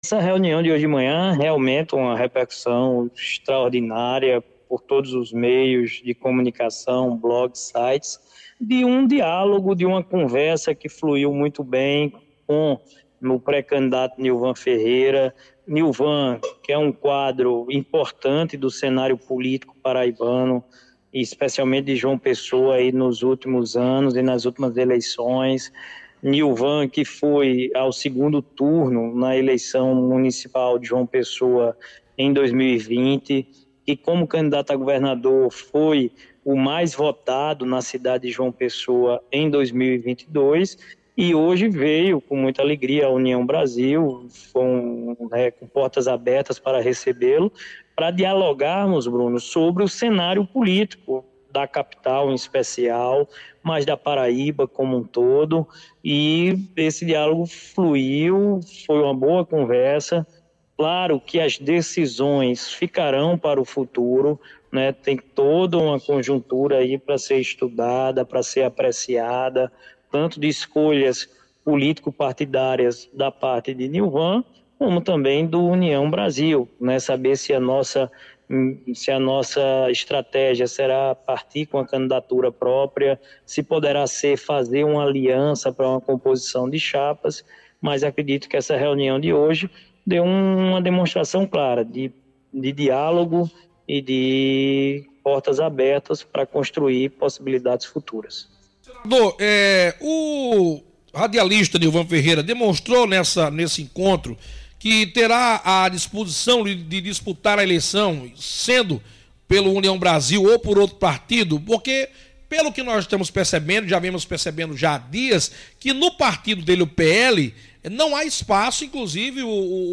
Efraim Filho revelou, em entrevista ao programa “60 Minutos”, do Sistema Arapuan de Comunicação, o desejo de ter uma candidatura própria do União Brasil na Capital. Ele enfatizou que o partido está preparado com estrutura, tempo de TV e recursos do fundo partidário para tornar o pleito equilibrado para Nilvan Ferreira.